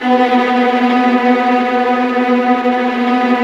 Index of /90_sSampleCDs/Roland L-CD702/VOL-1/STR_Vlas Bow FX/STR_Vas Tremolo